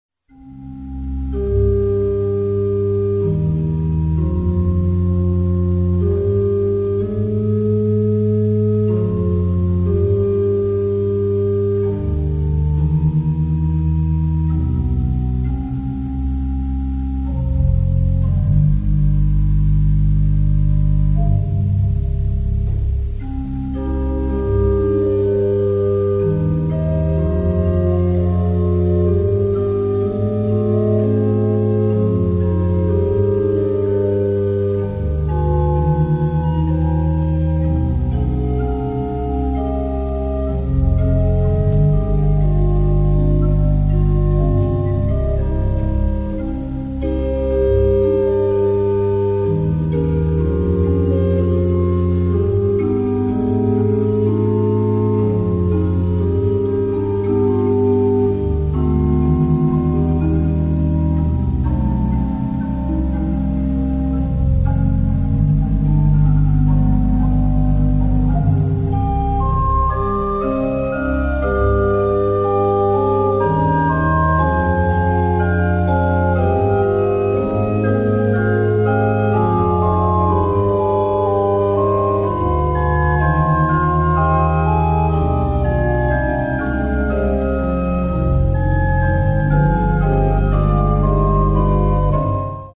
GROUND BASS (continued)
Listen to another Ground Bass this time by J.S. Bach from his "Passacaglia" in C minor." Notice how the "ground" or melody in the bass is stated alone before it is repeated over and over while the other voices spin polyphony above it.
groundbass2.mp3